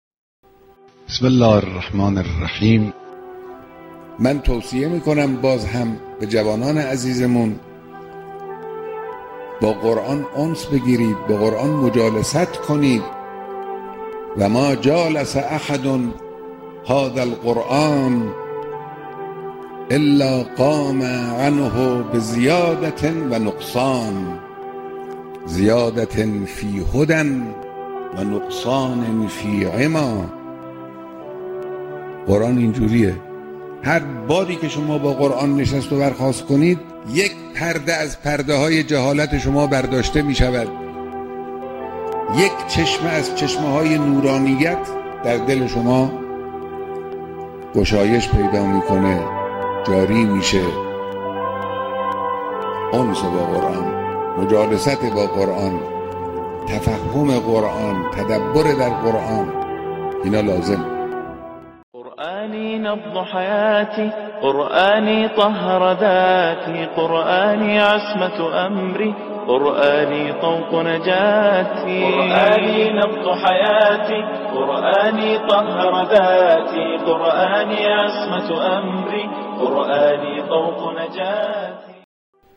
صوت بیانات رهبر معظم انقلاب